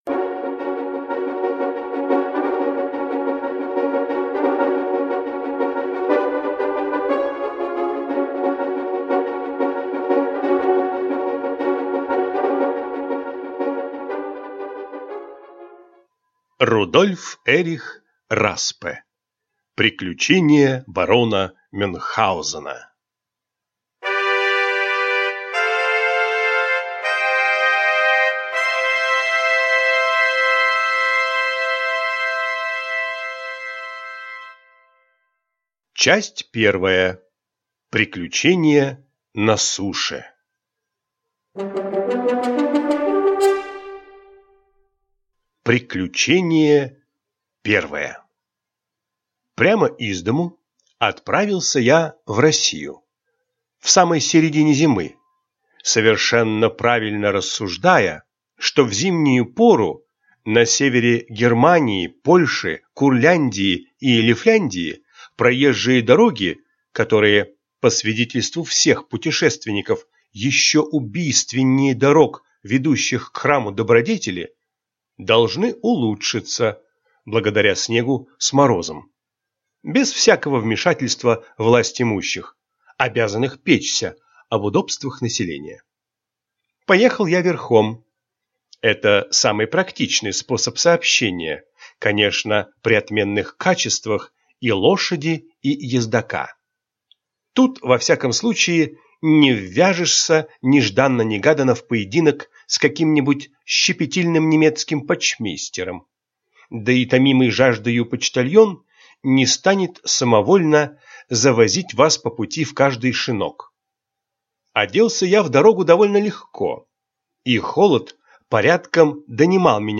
Аудиокнига Приключения барона Мюнхгаузена | Библиотека аудиокниг